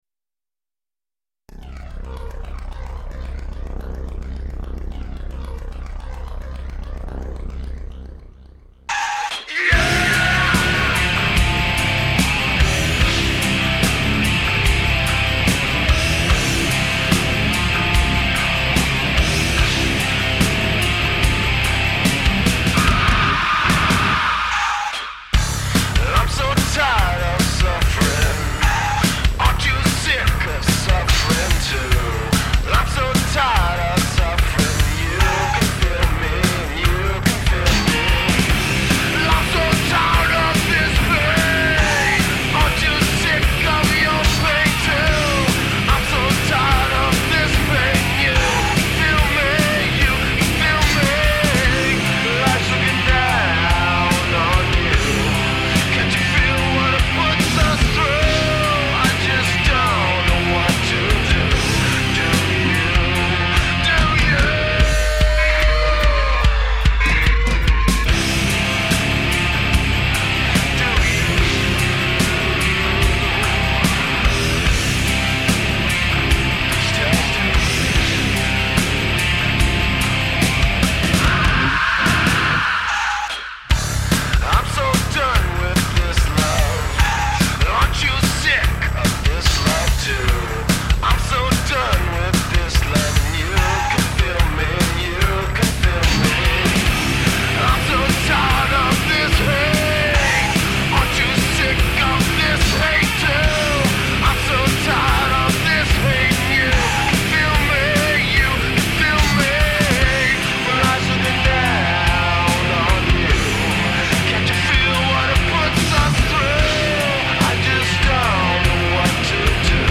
Heavy metal with strong melodies and big riffs.
Tagged as: Hard Rock, Other, Intense Metal